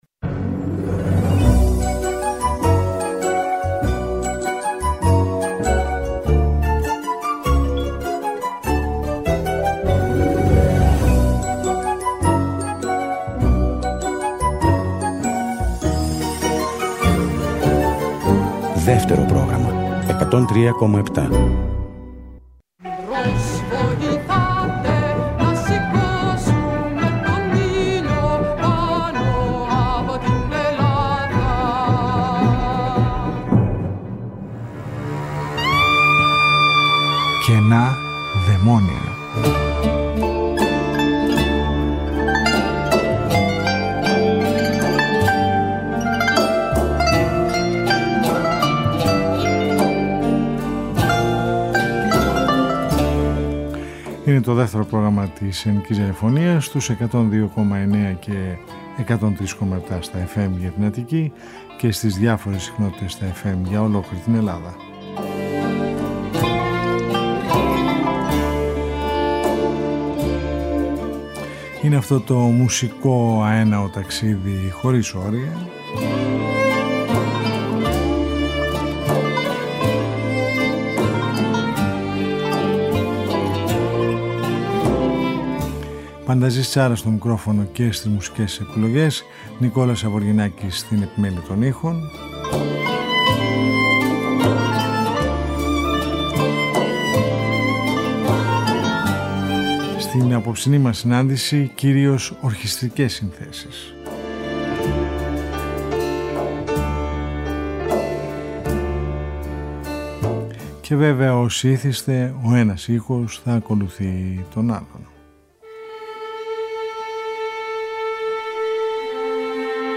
Μια ραδιοφωνική συνάντηση κάθε Σαββατοκύριακο που μας οδηγεί μέσα από τους ήχους της ελληνικής δισκογραφίας του χθες και του σήμερα σε ένα αέναο μουσικό ταξίδι.